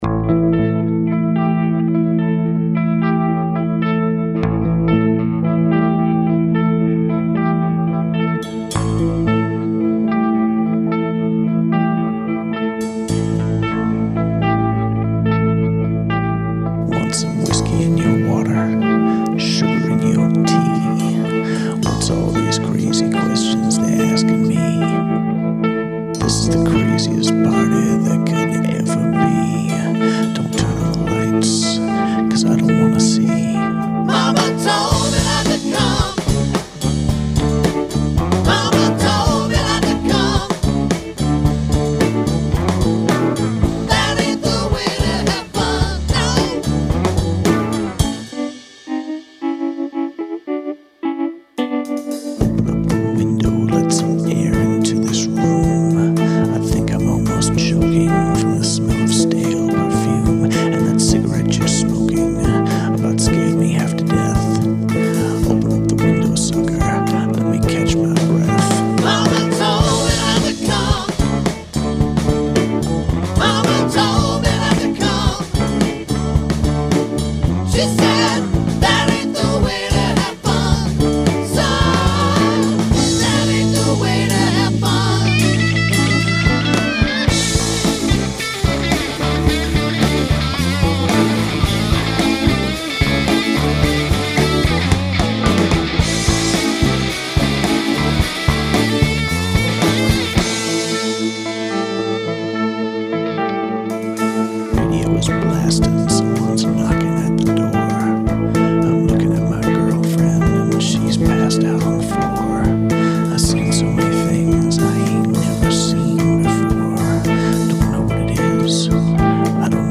impressive guitar solo